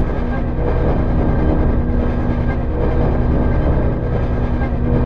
conjuration-magic-sign-rune-loop.ogg